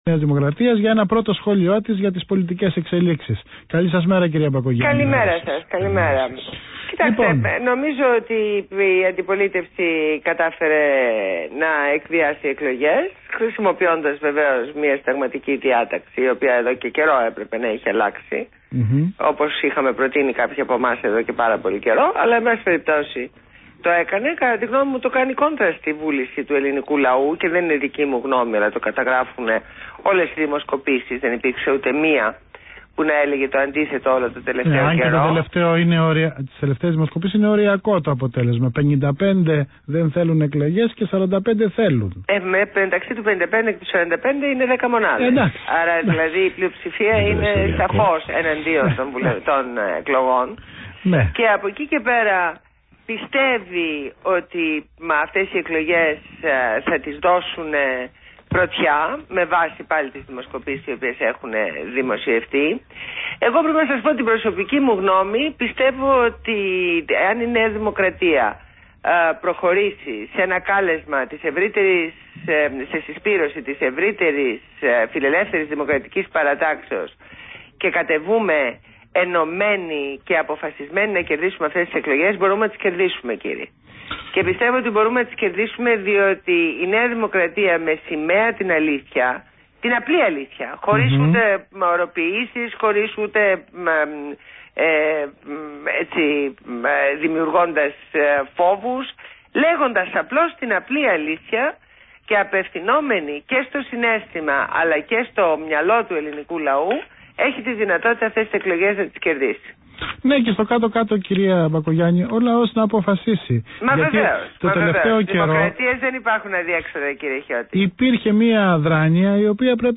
Συνέντευξη στο ραδιόφωνο ΒΗΜΑ FM